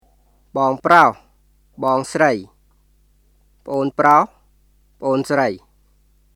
[ボーン（プロホ／スライ）、プオーン（プロホ／スライ）　 bɔːŋ(proh / srəi),　pʔoːn (proh / srəi)]